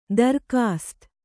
♪ darkāst